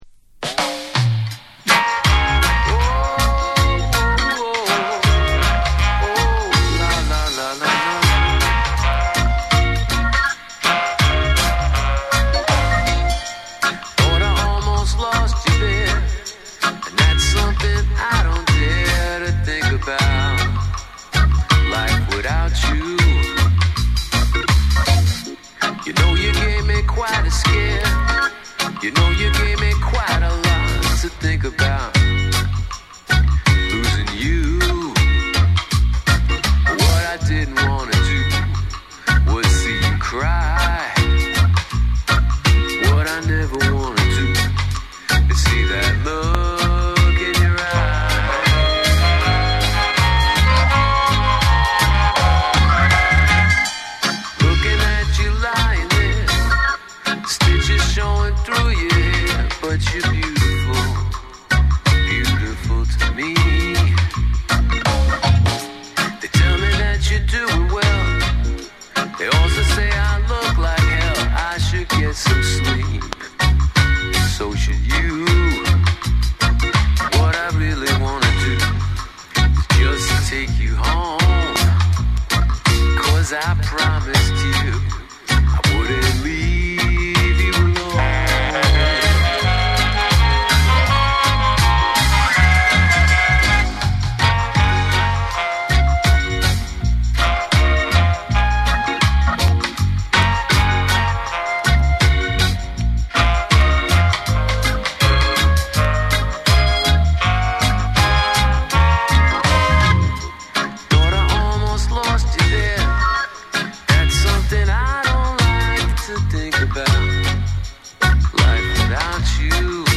オールディーズ〜ロックな雰囲気も漂わせるスカ・ナンバーを収録。
REGGAE & DUB